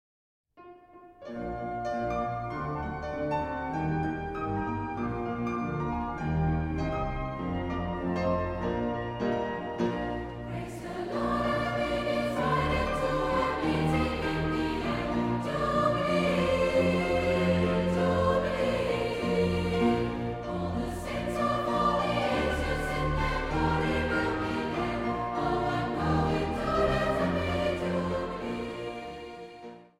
improvisaties en bekende klassieke werken op diverse orgels